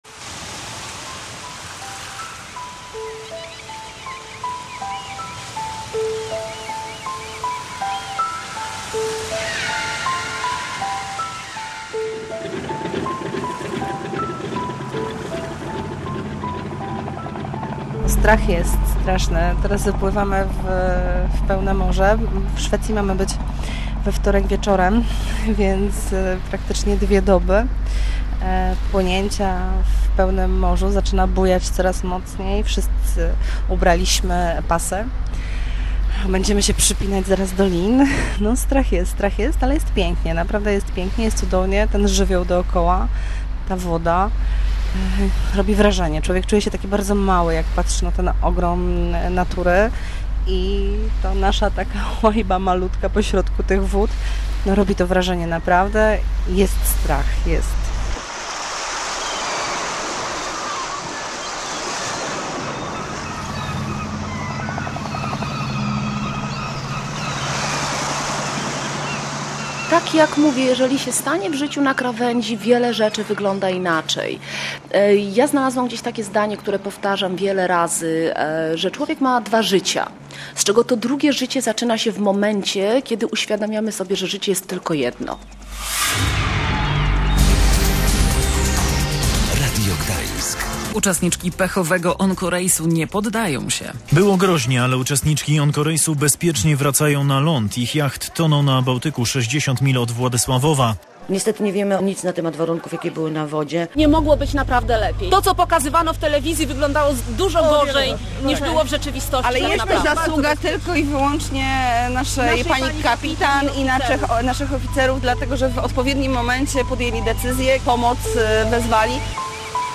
Co było dalej, o tym w reportażu